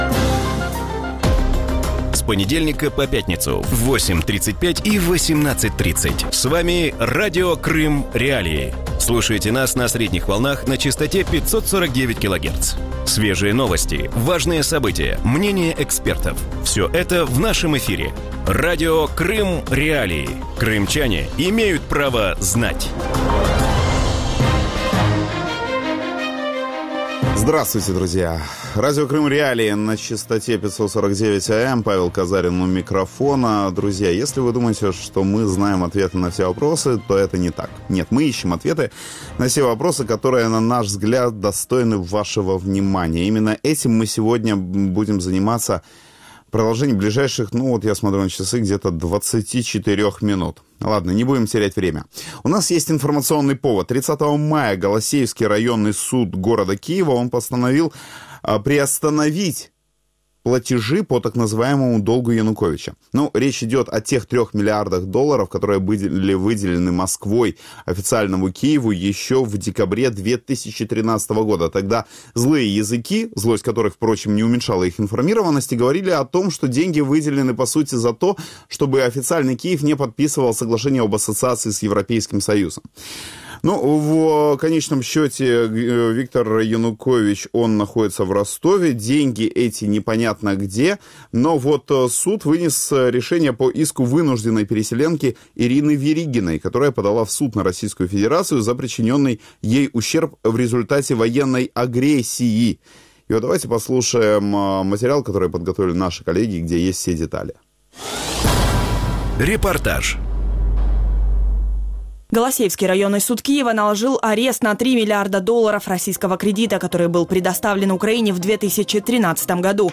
В вечернем эфире Радио Крым.Реалии обсуждают судебные тяжбы между Украиной и Россией из-за агрессии на Донбассе и в Крыму.